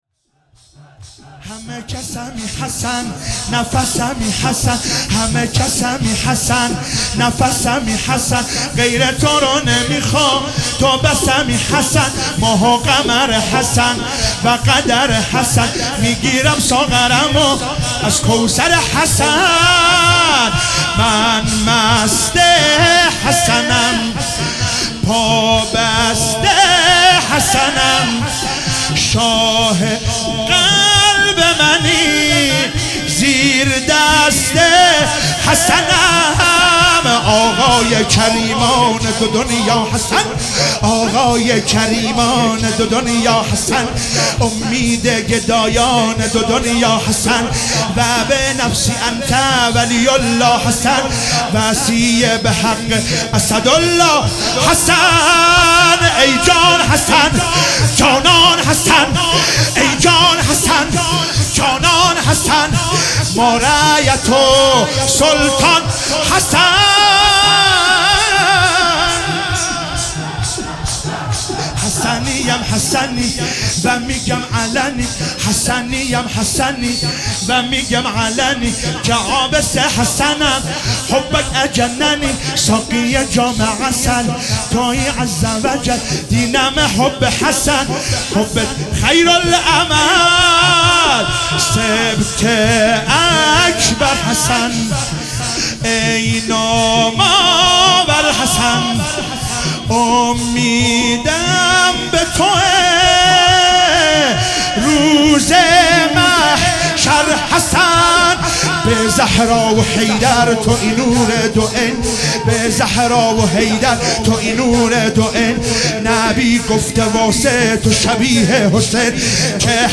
شب هشتم محرم96 - شور - همه کسمی حسن